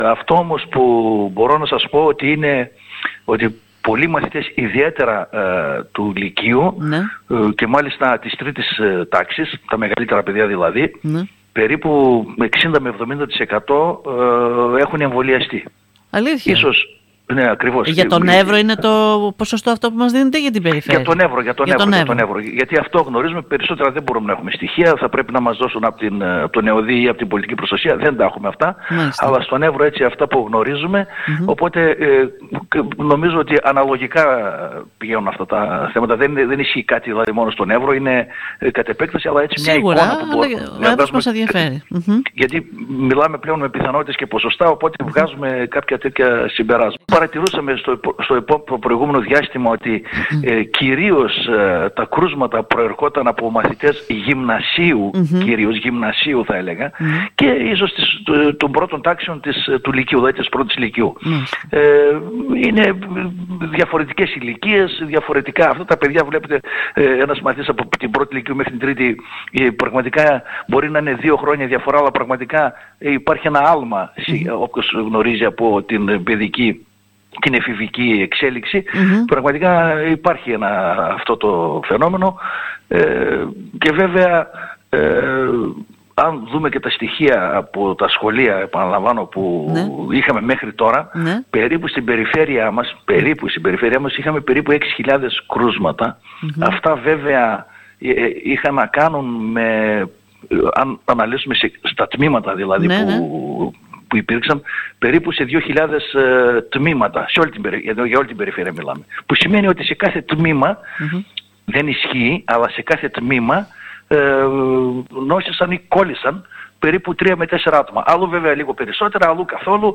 Χρήσιμα στατιστικά στοιχεία  σχετικά με την συμπεριφορά της Covid  στον μαθητικό πληθυσμό έδωσε σήμερα μιλώντας στην ΕΡΤ Ορεστιάδας ο θεματικός Αντιπεριφερειάρχης Υγείας ΑΜΘ κ. Κώστας Βενετίδης.